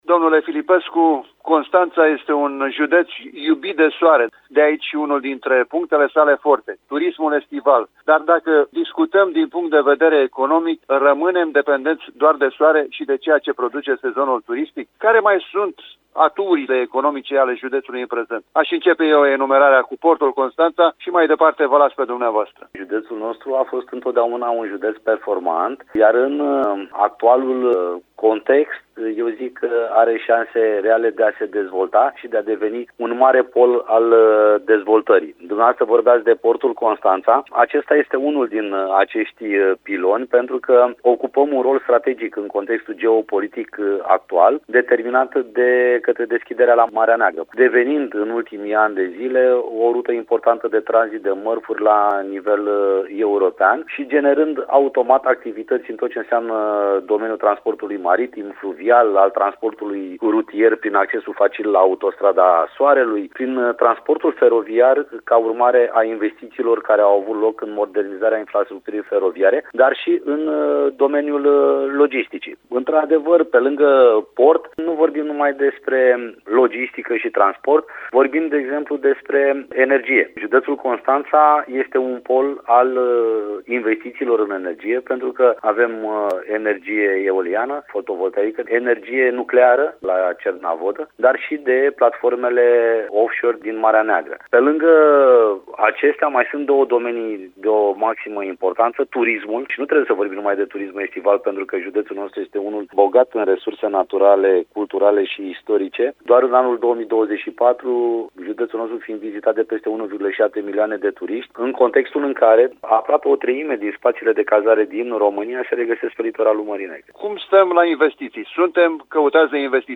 a vorbit pe această temă cu Răzvan Filipescu, vicepreședintele Consiliului județean